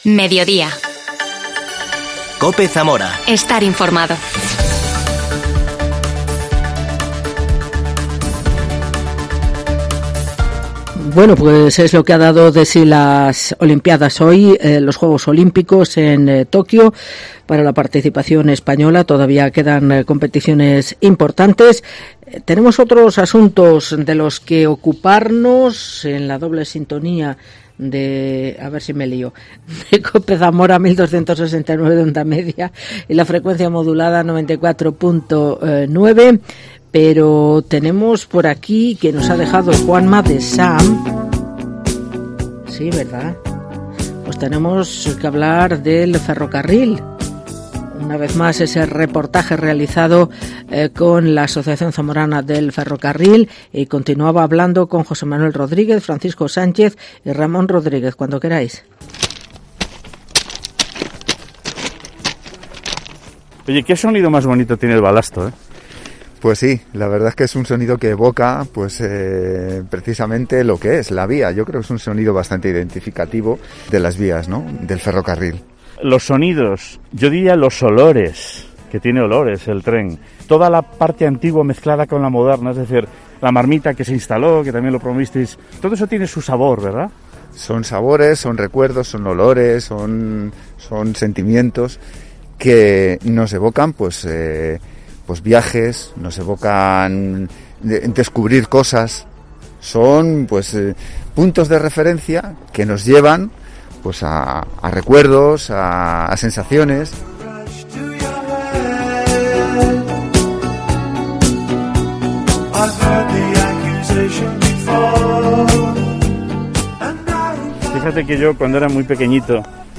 AUDIO: Reportaje de la Asociación Zamorana del Ferrocarril. II PARTE